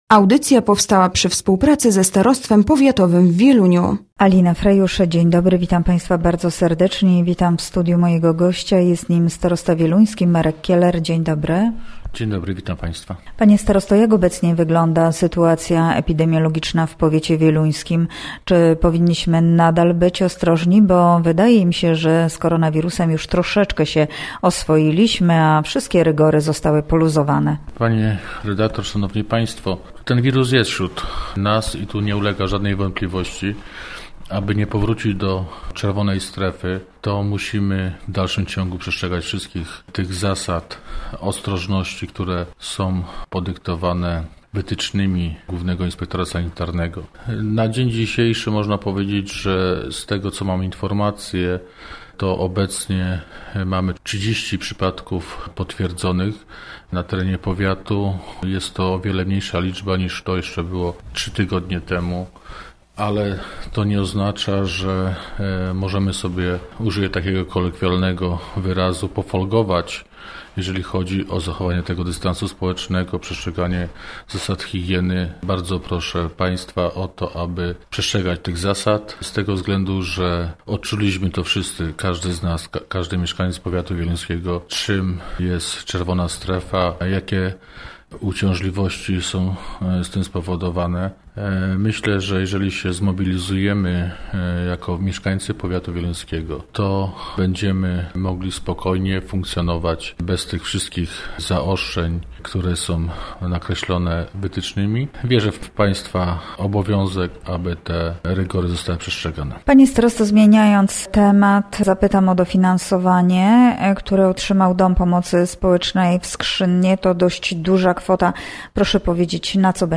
Gościem Radia ZW był Marek Kieler, starosta wieluński